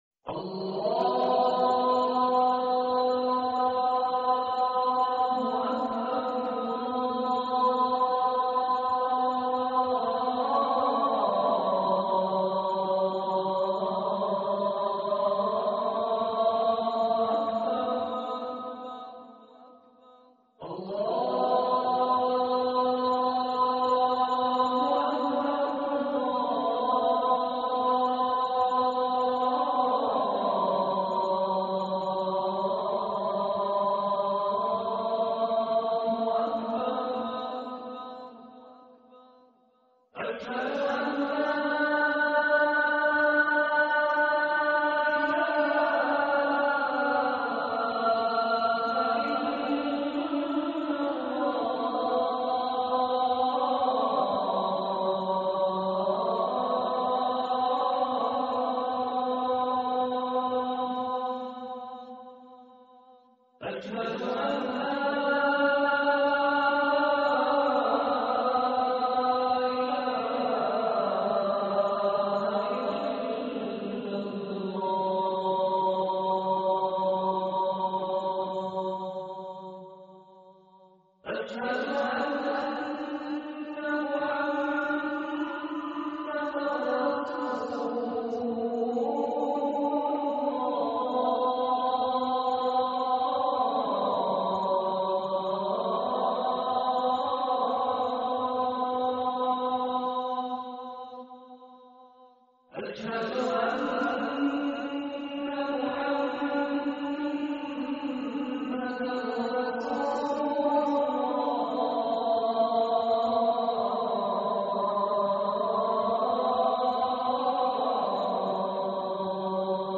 أذان القارئ الشيخ مشاري بن راشد العفاسي